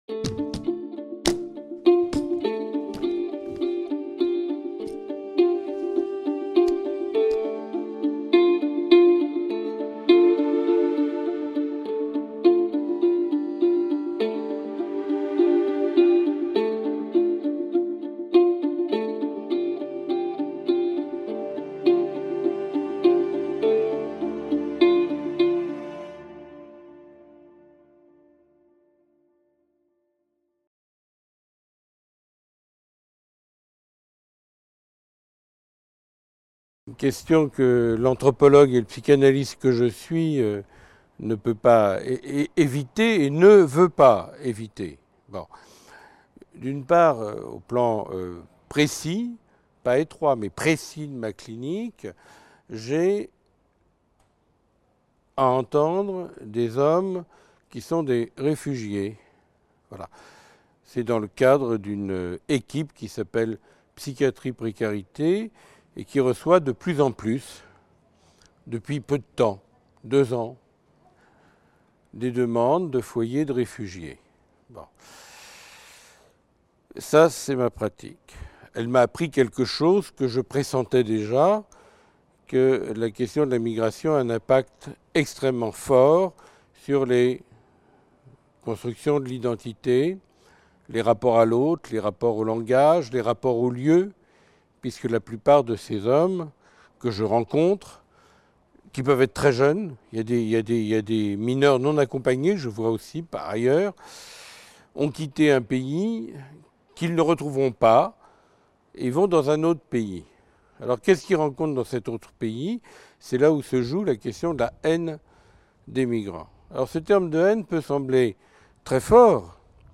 Table ronde organisée dans le cadre de la chaire Exil et migrations